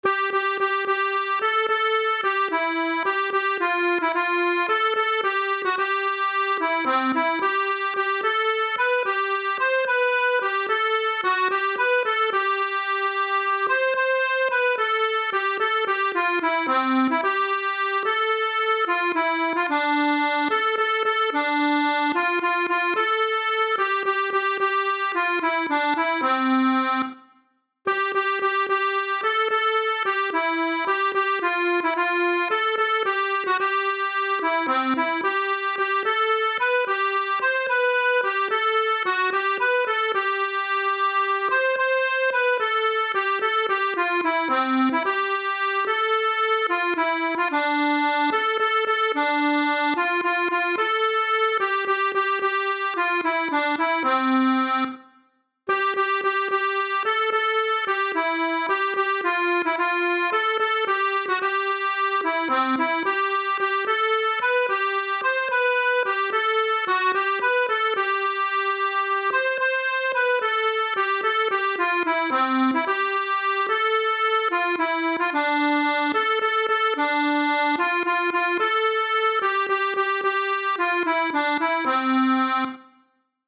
Chants de marins